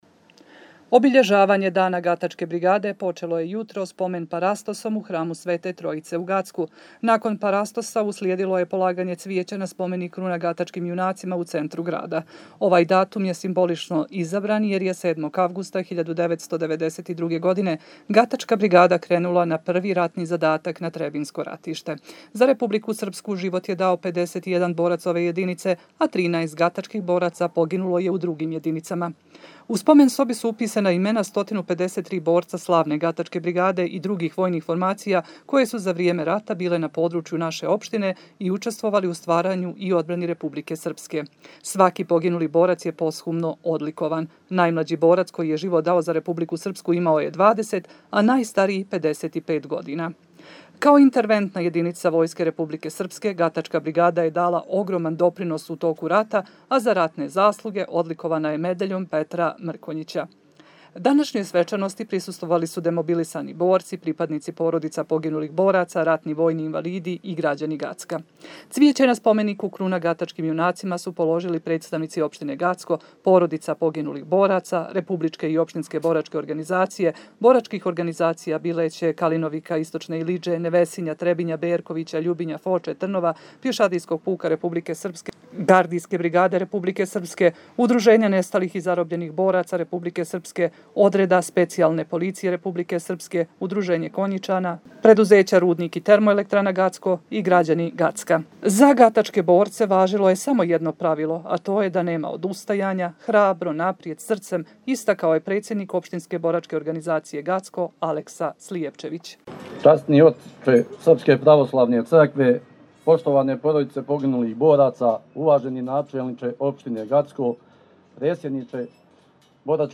Obilježavanje Dana Gatačke brigade počelo je jutros pomen parastosom u hramu Svete Trojice u Gacku.
Nakon porastosa uslijedilo je polaganje cvijeća na spomenik „Kruna gatačkim junacima“ u centru grada.